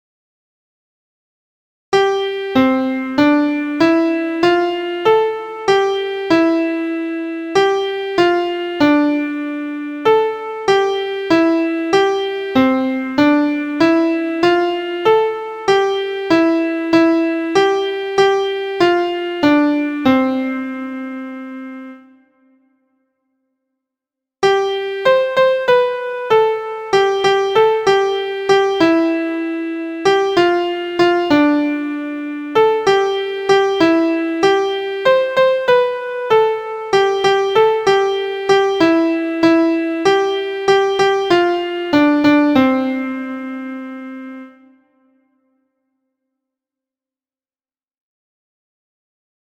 Syncopation pattern ti ta ti ta in 2/2 counted as 4/4.
• Key: D Major
• Time: 2/2 – counted in 4/4
• Form: AB verse/refrain
A popular American minstrel song.